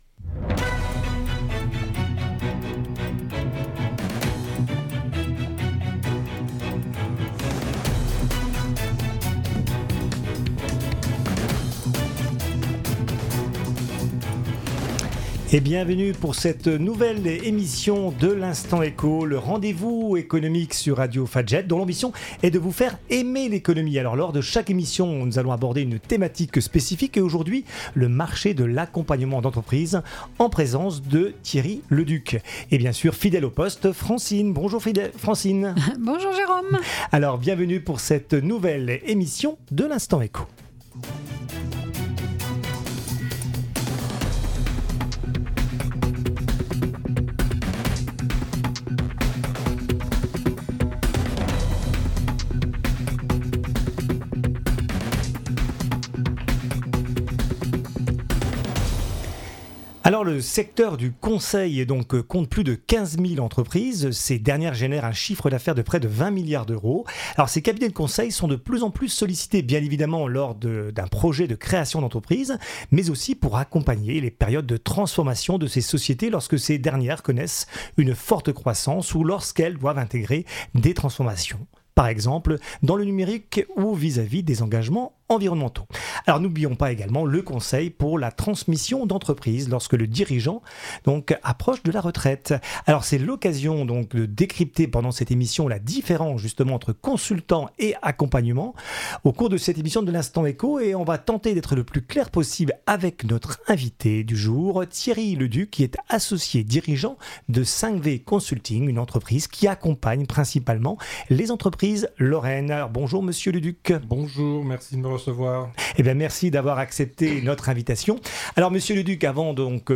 La radio nancéienne Fajet consacre chaque mois une émission à l'économie : l'Instant Éco.